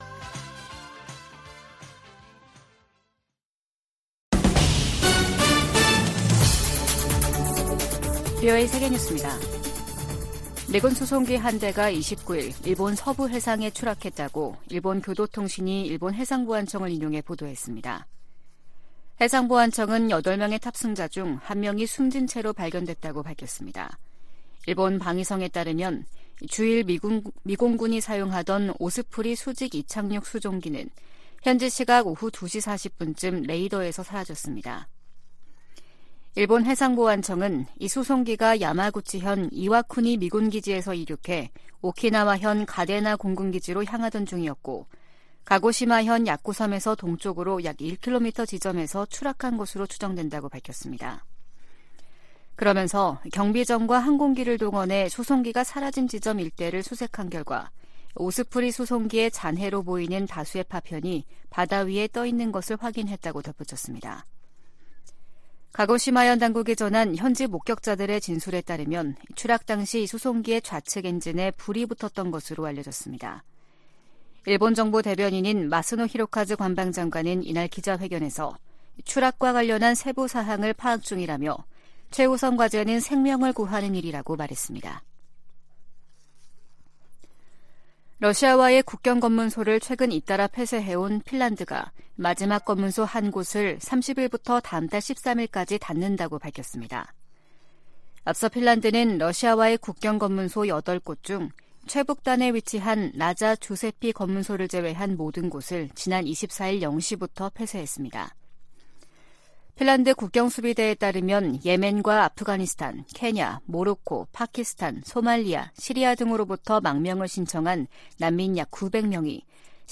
VOA 한국어 아침 뉴스 프로그램 '워싱턴 뉴스 광장' 2023년 11월 30일 방송입니다. 미국 정부는 북한의 정찰위성 발사를 규탄하면서 면밀히 평가하고, 러시아와의 협력 진전 상황도 주시하고 있다고 밝혔습니다. 미 국무부는 북한이 비무장지대(DMZ) 내 최전방 감시초소(GP)에 병력과 장비를 다시 투입한 데 대해 긴장을 부추기고 있다고 비판했습니다. 북한 정찰위성은 고화질 사진을 찍을 수 없다고 전문가들이 평가했습니다.